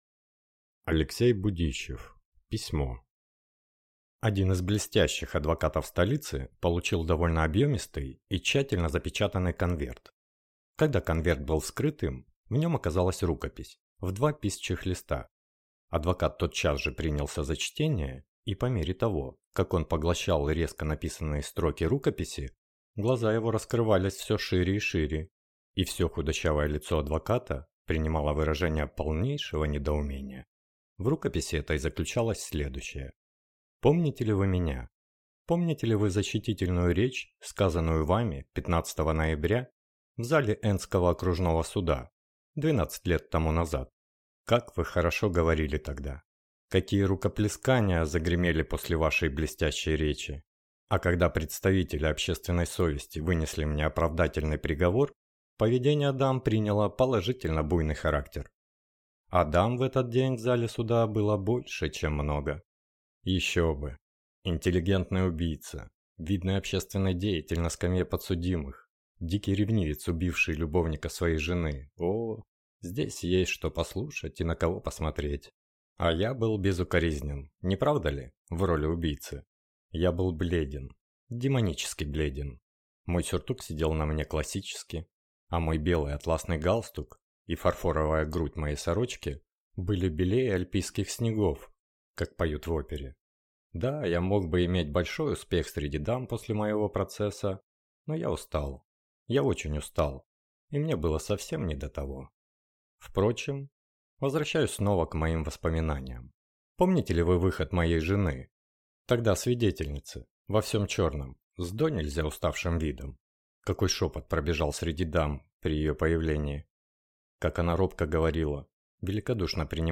Аудиокнига Письмо | Библиотека аудиокниг